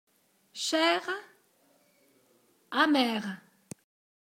• s'il est précédé de e, on ne le prononce que dans quelques adjectifs monosyllabiques comme : cher, amer